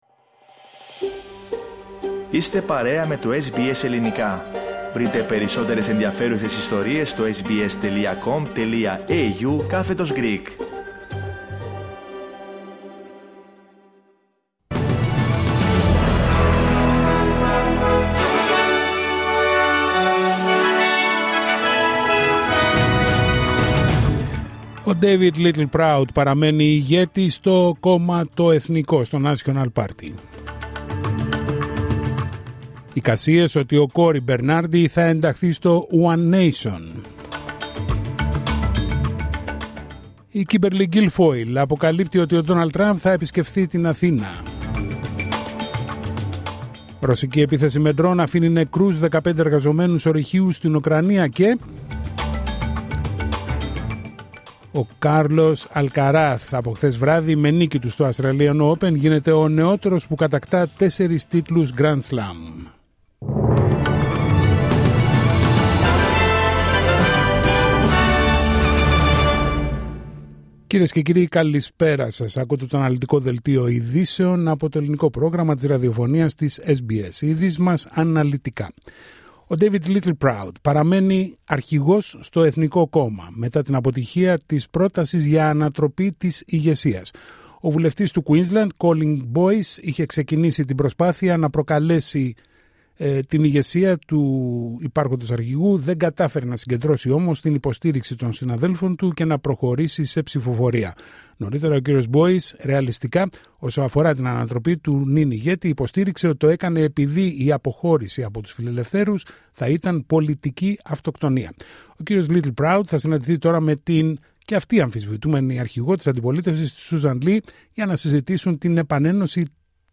Δελτίο ειδήσεων Δευτέρα 2 Φεβρουαρίου 2026